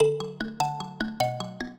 mbira
minuet8-5.wav